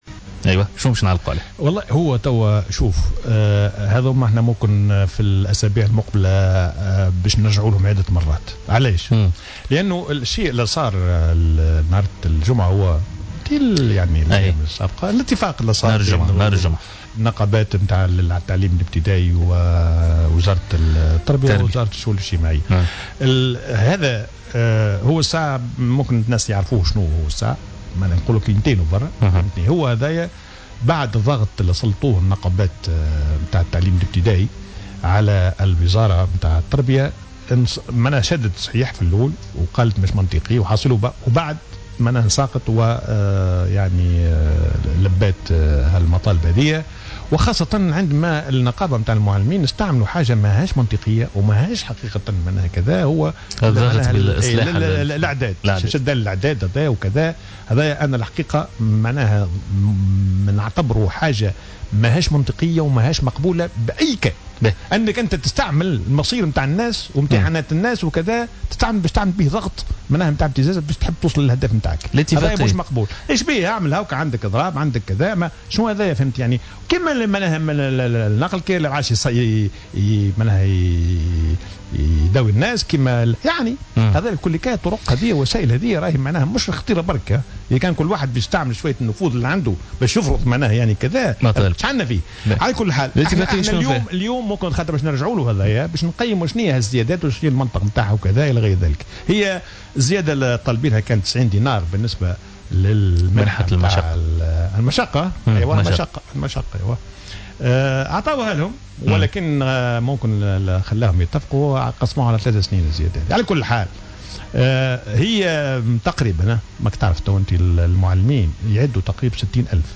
L'expert en économie et ancien ministre des Finances, Houcine Dimassi, a assuré sur nos ondes que l'accord relatif à l'augmentation des salaires dans le secteur privé, et qui sera signé aujourd'hui même au palais du gouvernement, ouvrira la voie vers de plus en plus de revendications, ce qui aura un impact négatif sur l'économie du pays, déjà surendetté.